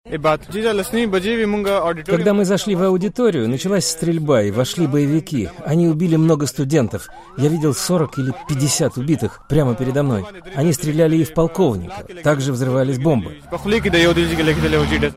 Главный министр провинции Хайбер-Пахтунхва Первез Хаттак сказал журналистам